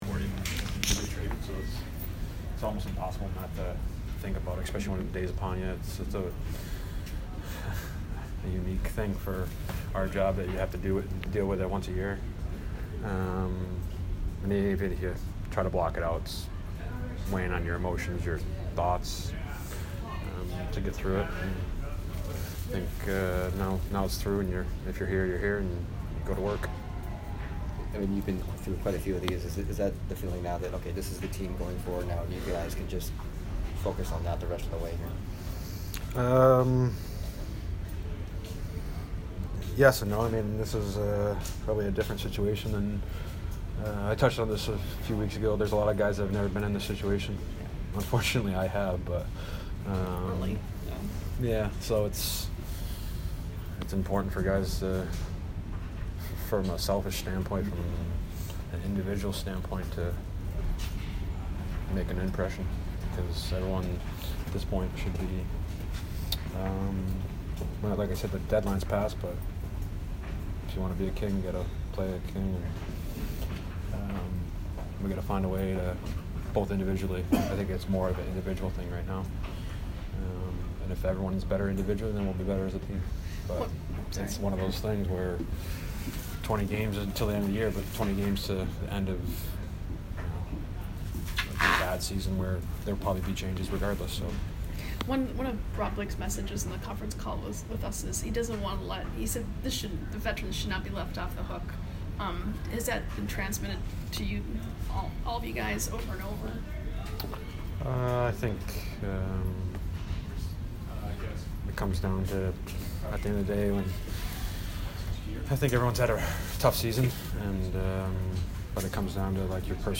Dustin Brown post-game 2/25